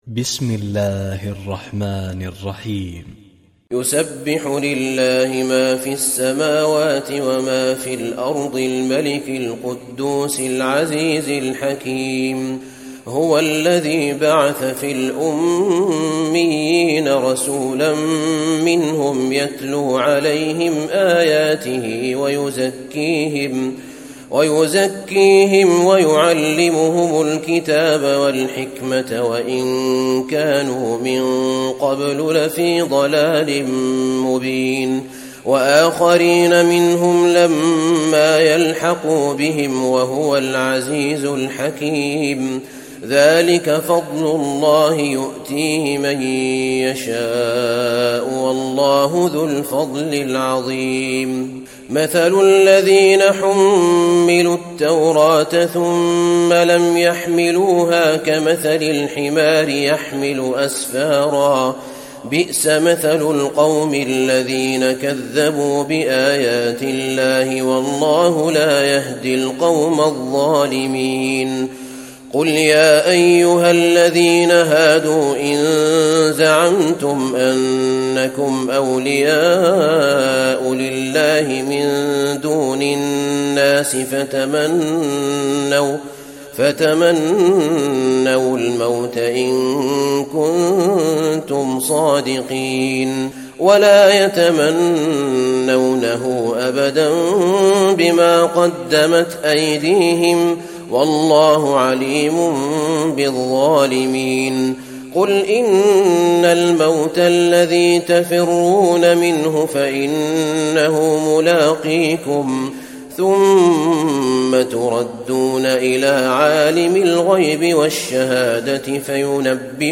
تراويح ليلة 27 رمضان 1435هـ من سورة الجمعة الى التحريم Taraweeh 27 st night Ramadan 1435H from Surah Al-Jumu'a to At-Tahrim > تراويح الحرم النبوي عام 1435 🕌 > التراويح - تلاوات الحرمين